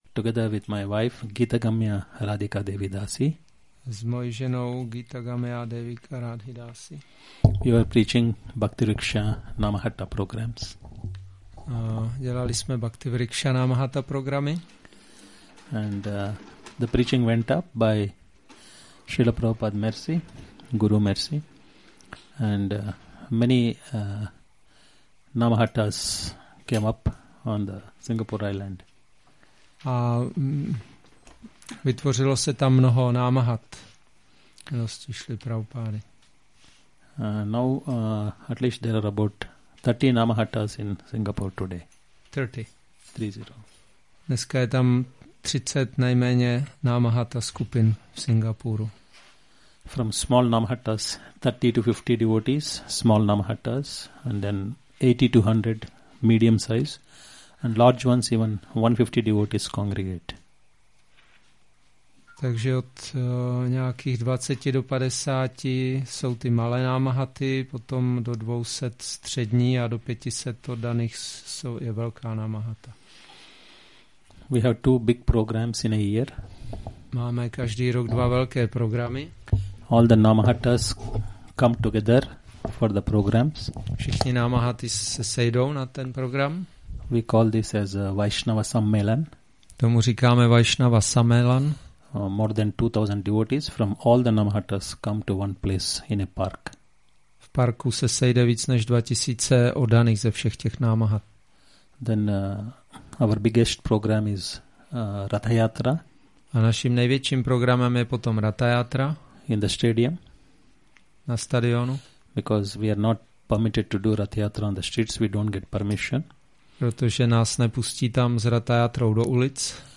Přednáška BG-6.47